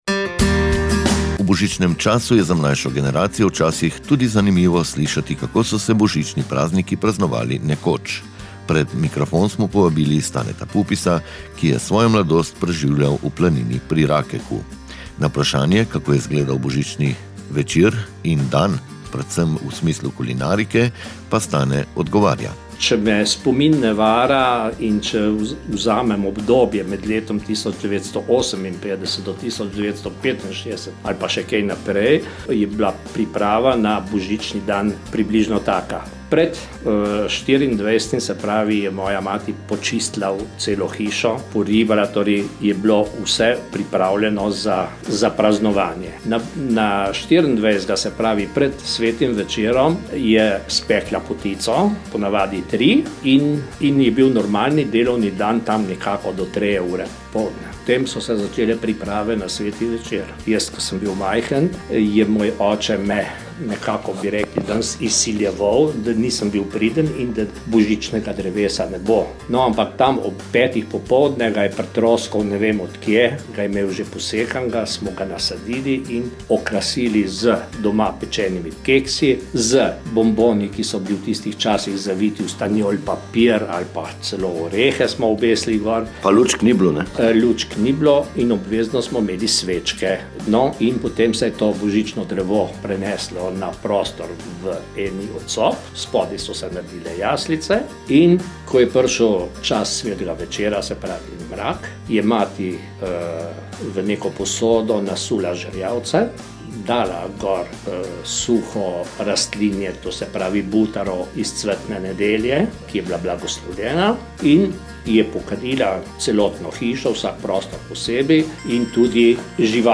nam je v pogovoru zaupal. kako je bilo v 50-ih in 60-ih letih minulega stoletja.